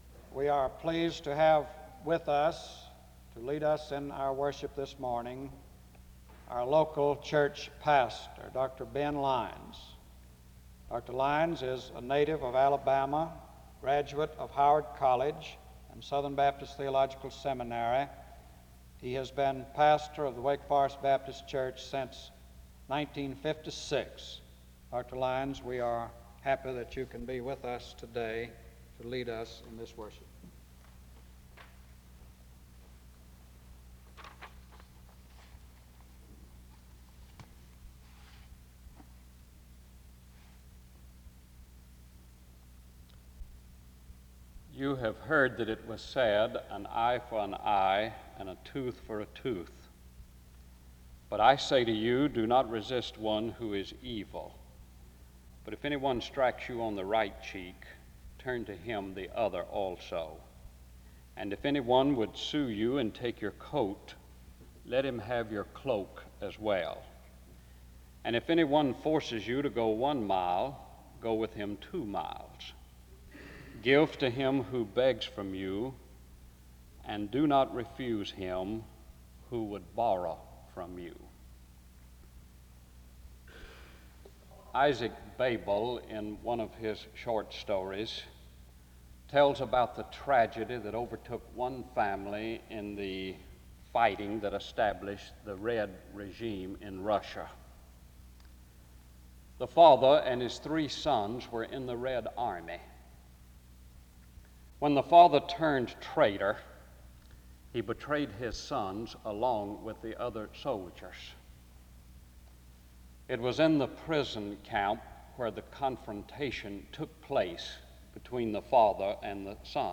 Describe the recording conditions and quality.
SEBTS Chapel